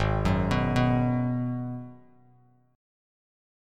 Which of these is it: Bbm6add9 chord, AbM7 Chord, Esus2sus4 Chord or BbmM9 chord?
AbM7 Chord